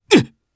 Clause-Vox_Damage_jp_02.wav